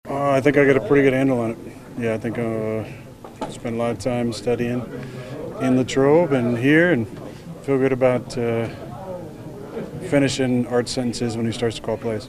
Quarterback Aaron Rodgers held his weekly media session and said he feels quite comfortable now in the Steelers’ offense, despite not having seen game action in the preseason.